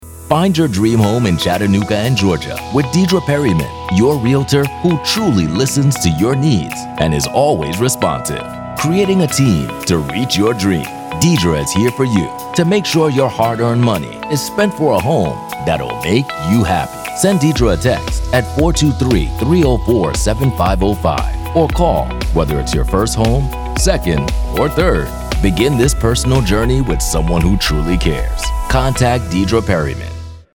Realtor Ad